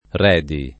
[ r $ di ]